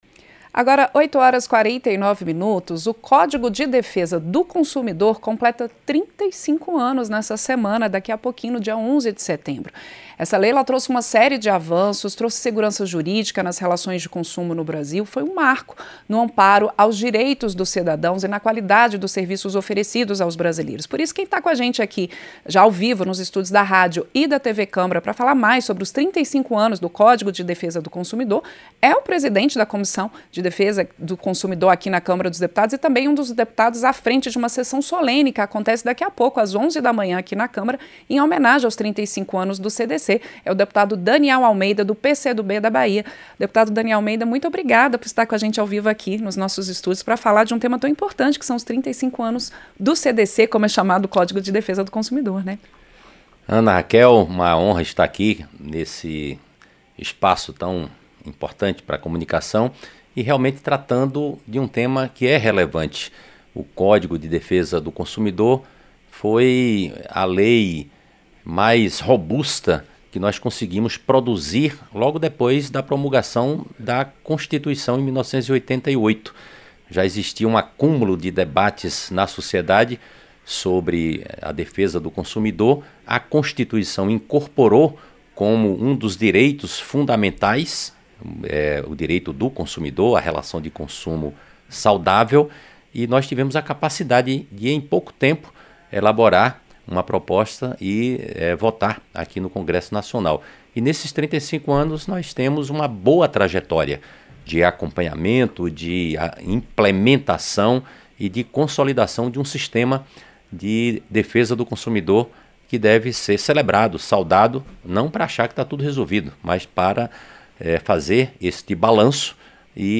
Entrevista: Deputado Daniel Almeida (PCdoB-BA)